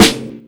Live_Snro (3).wav